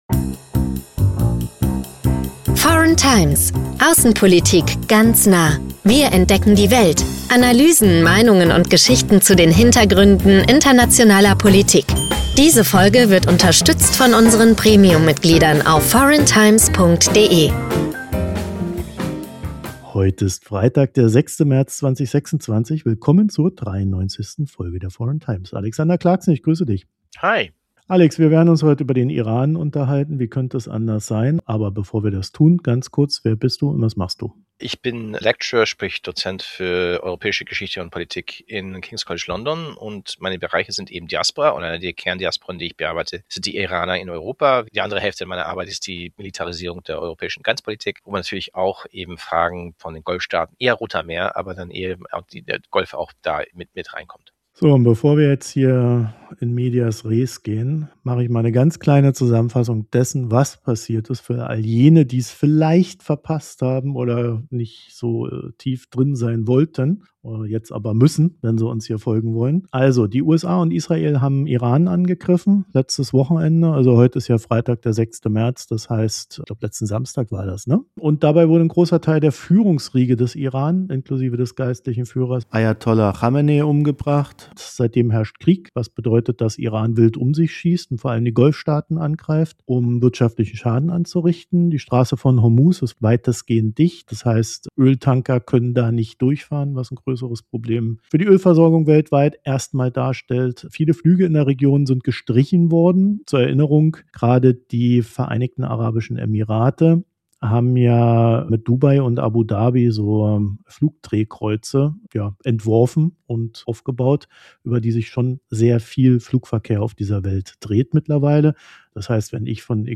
In diesem Gespräch analysieren wir die aktuellen geopolitischen Entwicklungen im Nahen Osten, insbesondere die Spannungen zwischen den USA, Israel und dem Iran.